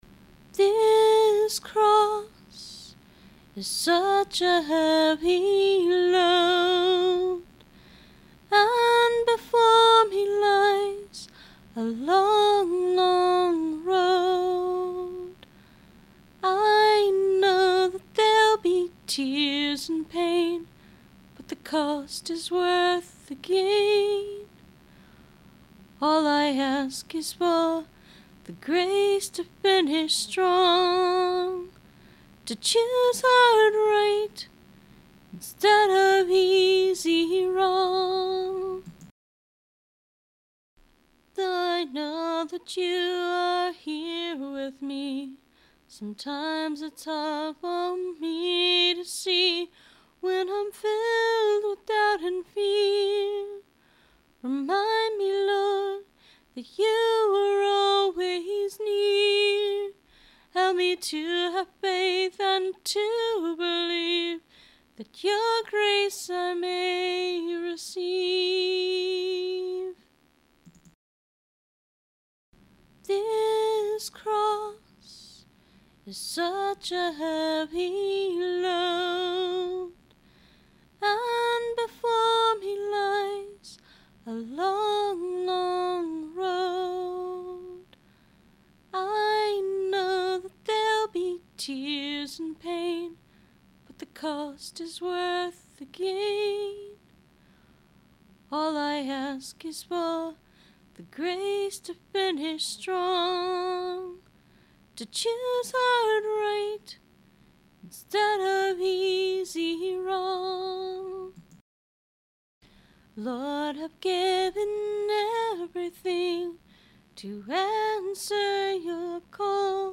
Free Christian Music